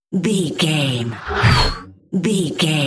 Sci fi horror whoosh fast
Sound Effects
Atonal
Fast
tension
ominous
eerie